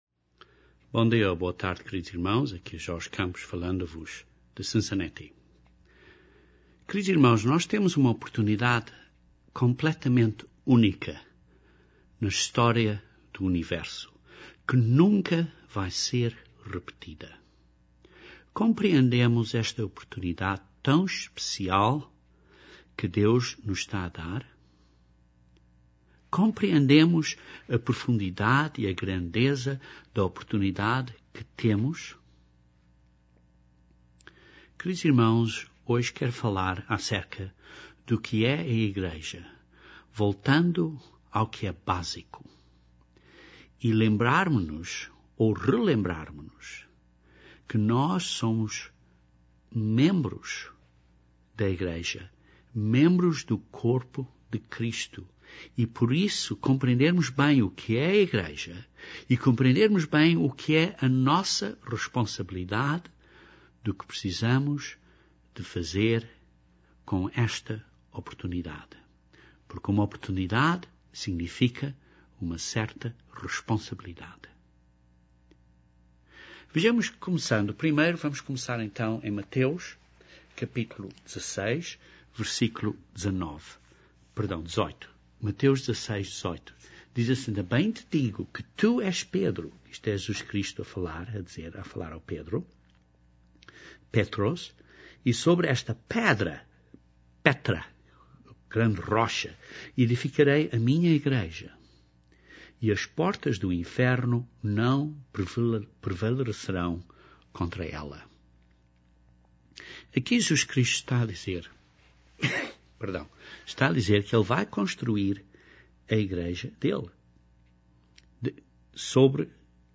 Este sermão volta a lembrar-nos a nossa responsibilidade de estarmos unidos e a fazer a Obra de Deus.